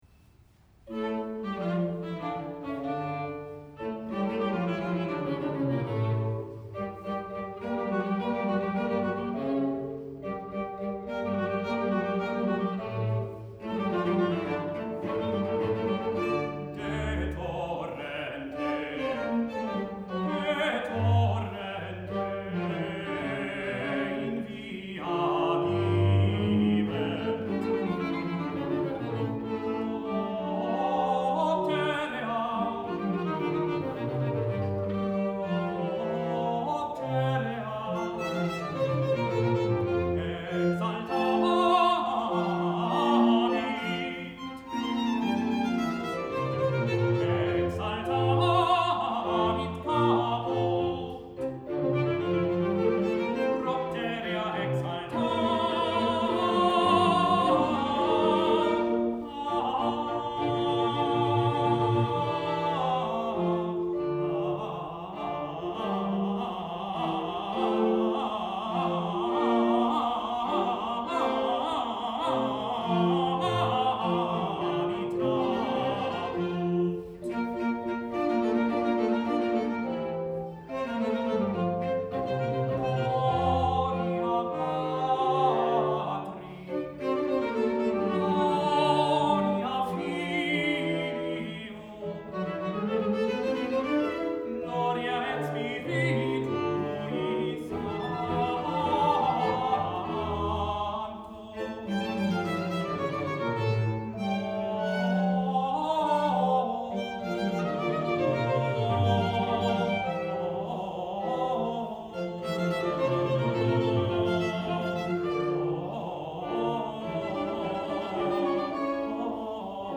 Brookline High School Camerata & Advanced Chamber Orchestra
Saint Paul’s Church, Brookline, MA